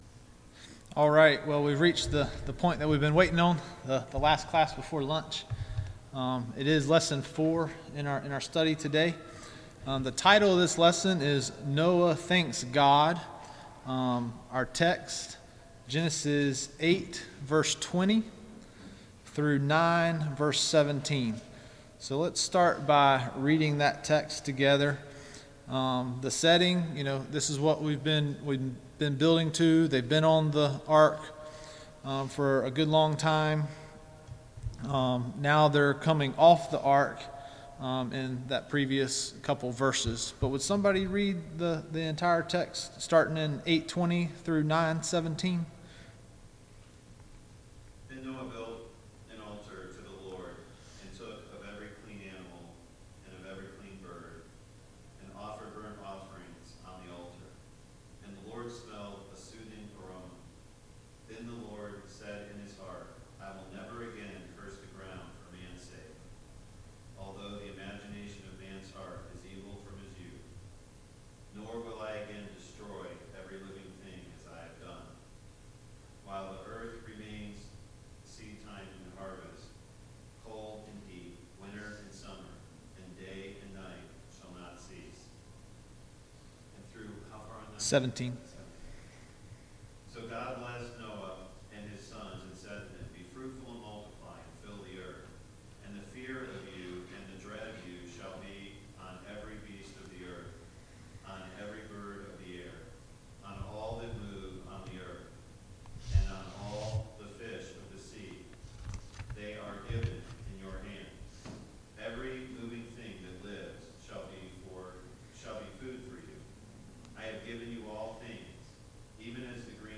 Genesis 8:20-9:17 Service Type: VBS Adult Class « Lesson 3